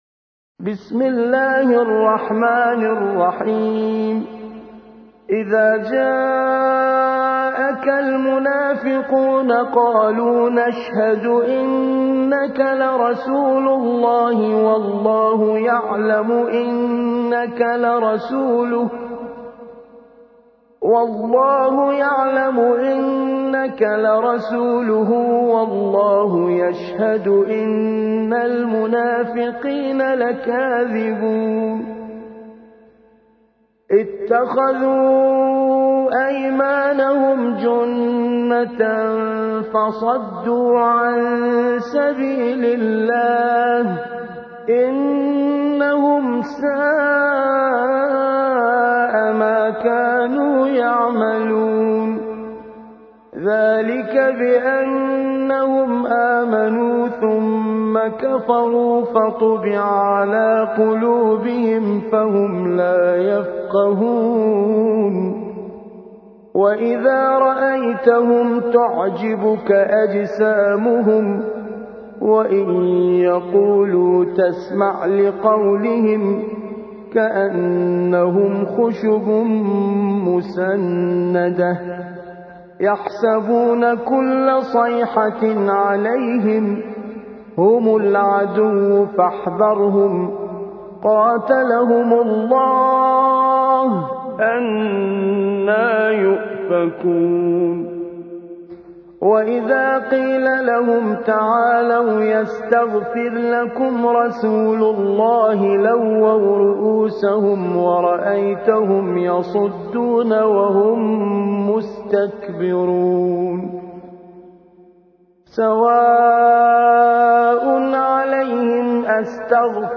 63. سورة المنافقون / القارئ